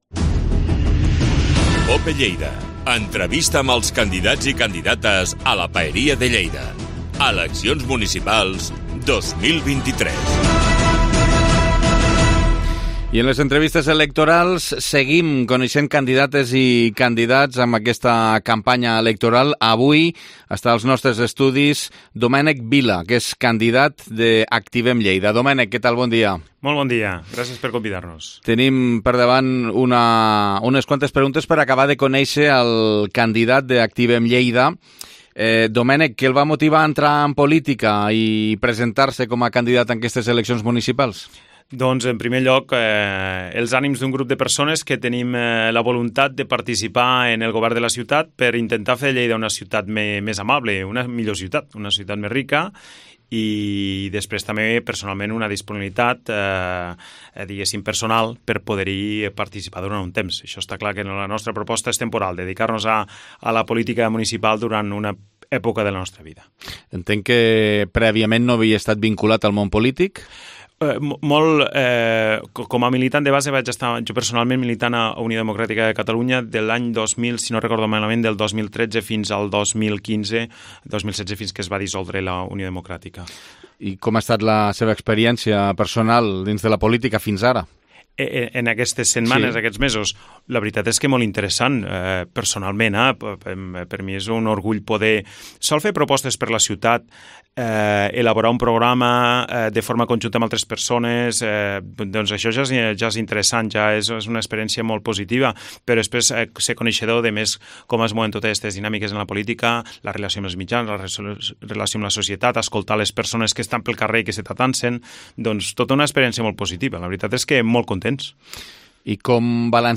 Entrevista Campanya Electoral 2023